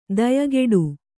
♪ dayageḍu